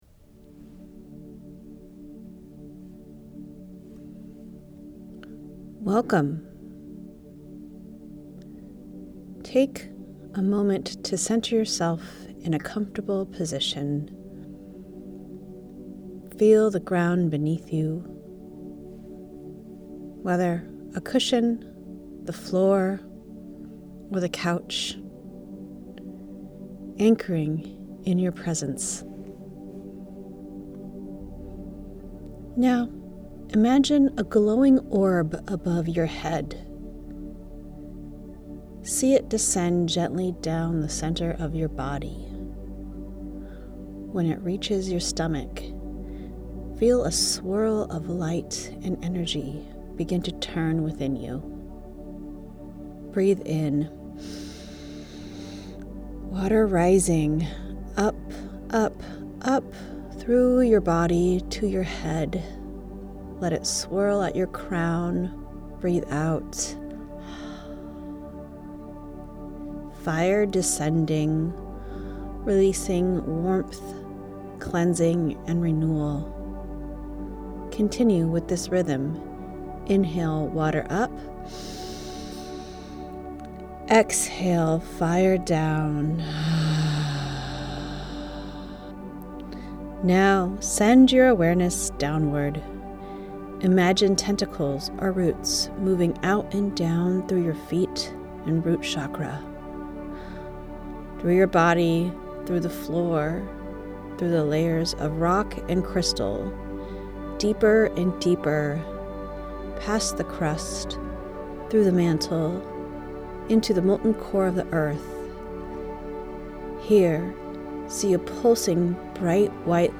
🌕 Elemental Ascension & Return: A Guided Meditation for Clearing, Calling, and Returning Home to Wholeness